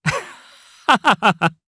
Siegfried-Vox_Happy3_jp.wav